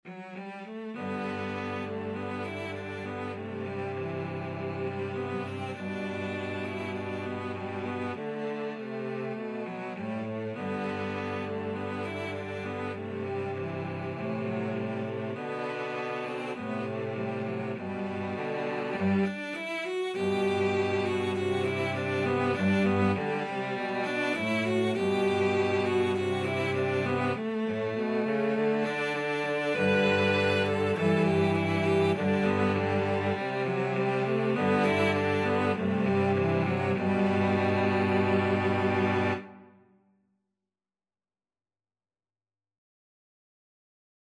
G major (Sounding Pitch) (View more G major Music for Cello Trio )
Espressivo
4/4 (View more 4/4 Music)
Cello Trio  (View more Intermediate Cello Trio Music)
Traditional (View more Traditional Cello Trio Music)